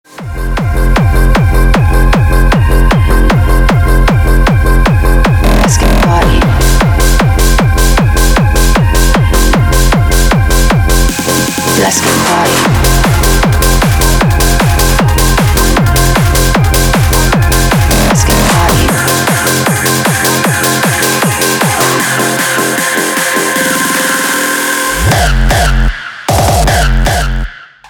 Энергичный рингтон
Клубные рингтоны